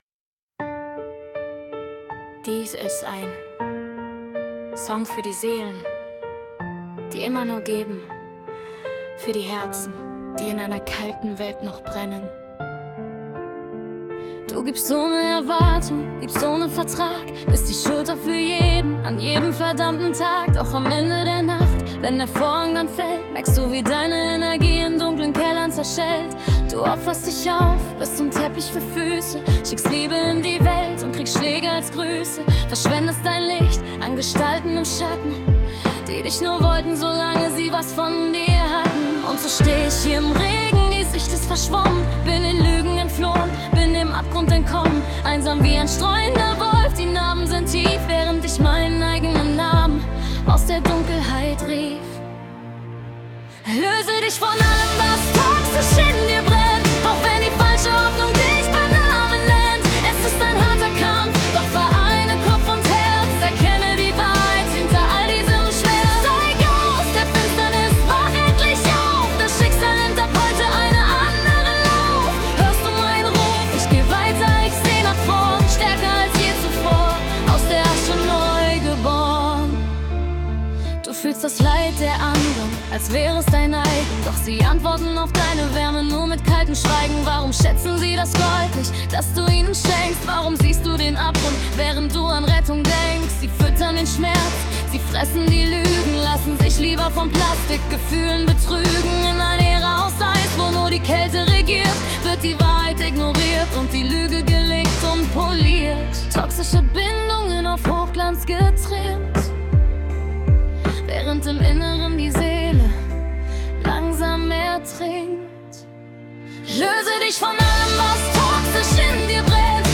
Pop Version